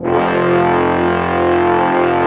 55m-orc02-F1.wav